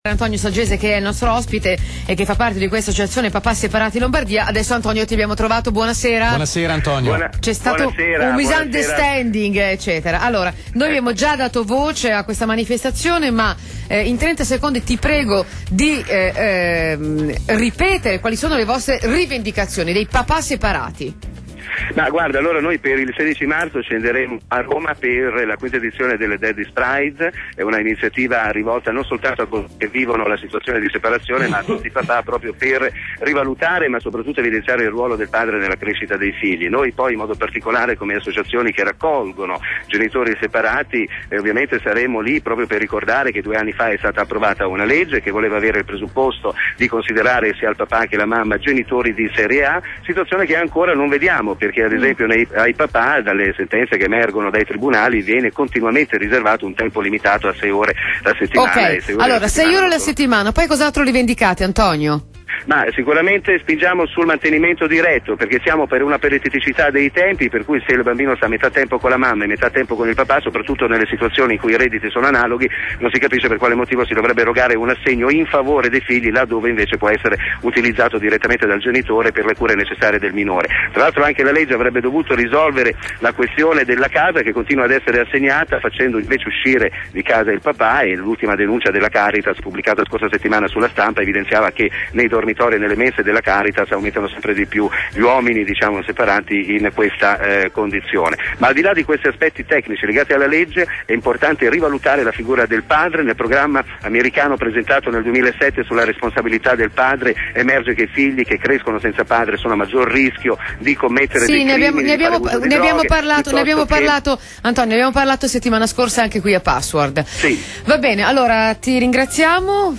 L'Associazione Papà Separati Lombardia, in diretta telefonica su RTL 102.5, alle 17,05 del 21.02.2008